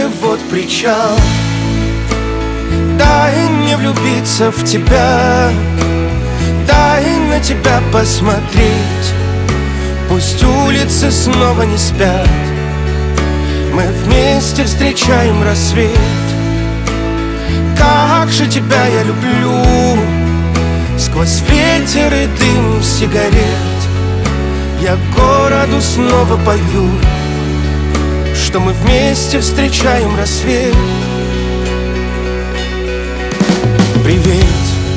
Жанр: Русская поп-музыка / Поп / Рок / Русский рок / Русские